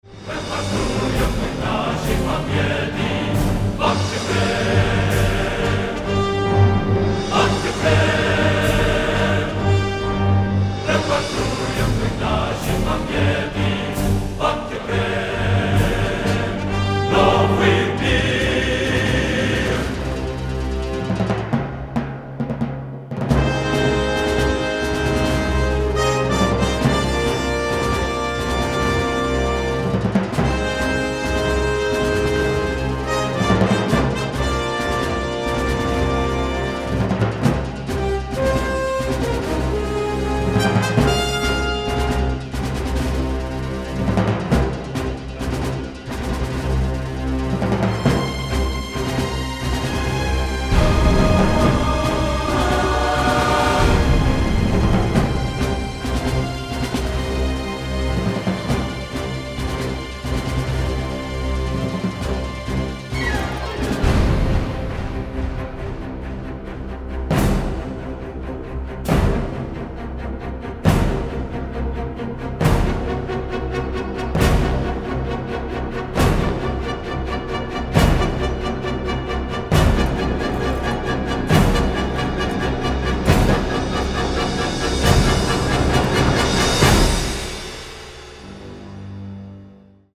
contemporary electronics